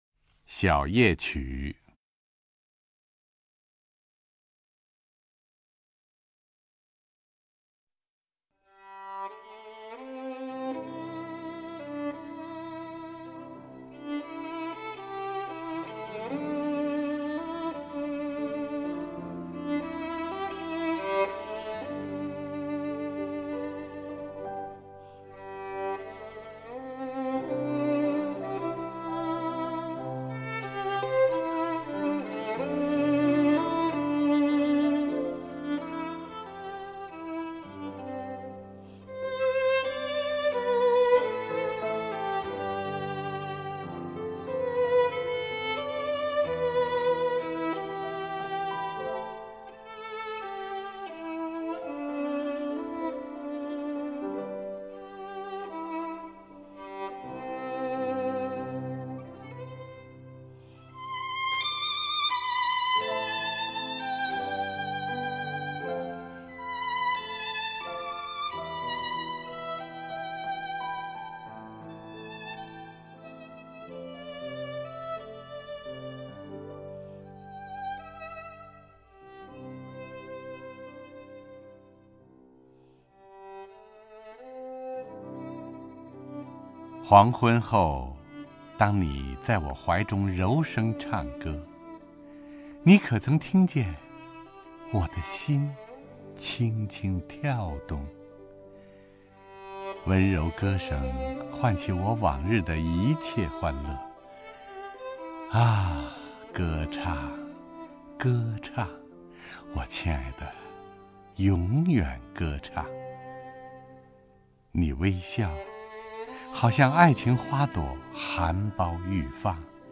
首页 视听 名家朗诵欣赏 陈铎
陈铎朗诵：《小夜曲》(（法）维克多-马里·雨果)　/ （法）维克多-马里·雨果